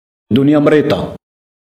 dm_prononciation.mp3